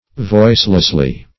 voicelessly - definition of voicelessly - synonyms, pronunciation, spelling from Free Dictionary
[1913 Webster] -- Voice"less*ly, adv. --